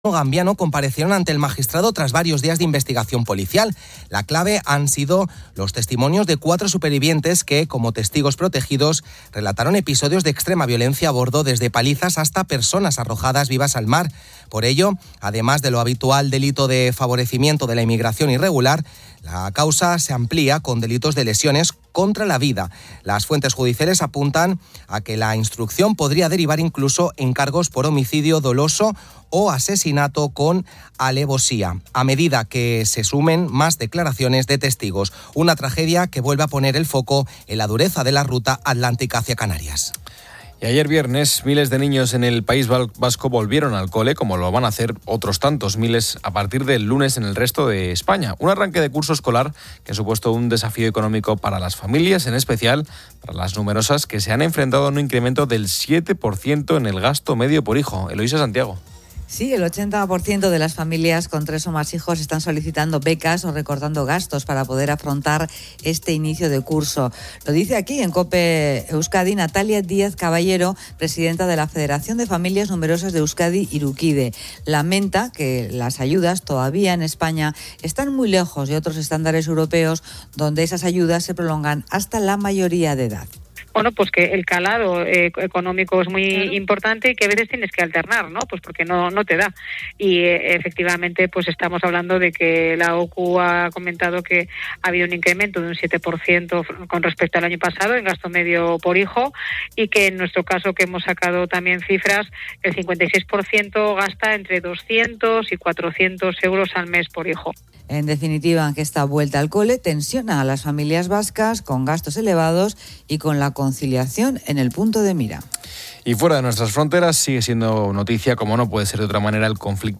El programa "Poniendo las Calles" analiza la situación de los incendios en Castilla-La Mancha y el valor del trabajo de bomberos y fuerzas de seguridad. Se entrevista a la viceconsejera Gracia Canales sobre la "Escuela de Pastores", que aborda la escasez de profesionales y promueve la dignidad del sector ganadero, crucial también para la prevención de incendios.